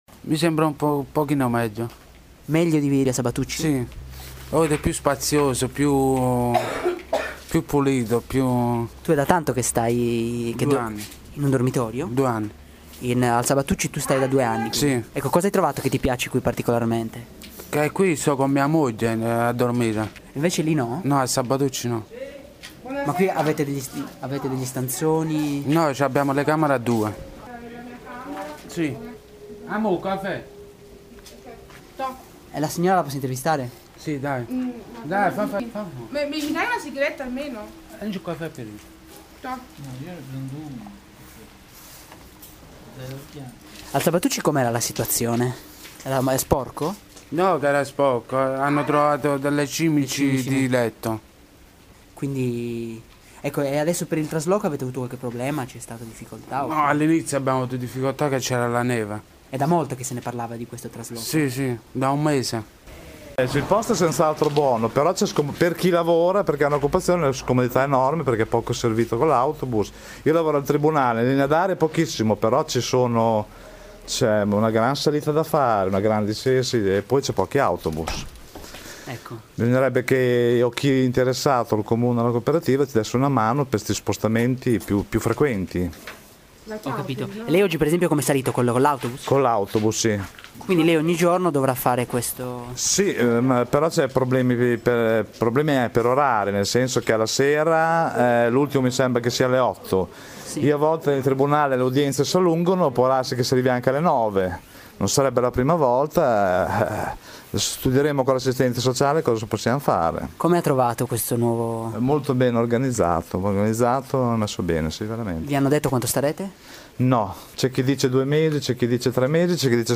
Abbiamo raccolto alcune voci delle persone di Villa Aldini